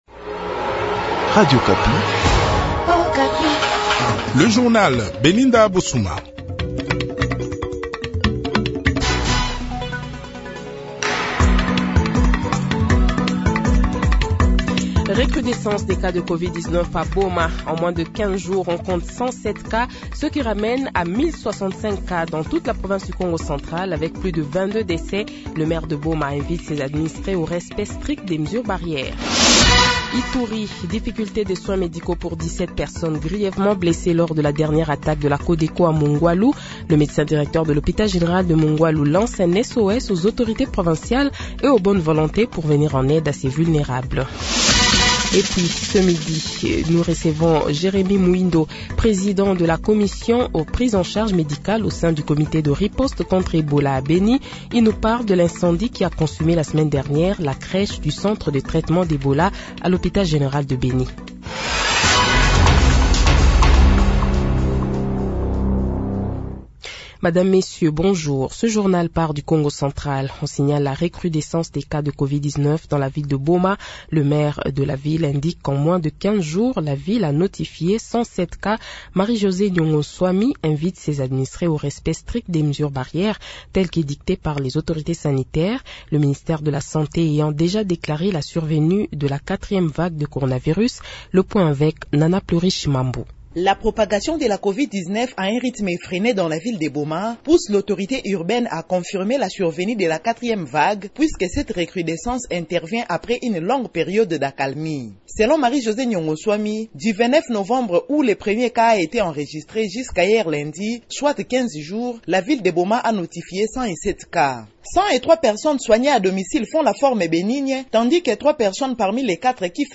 Le Journal de 12h, 14 Decembre 2021 :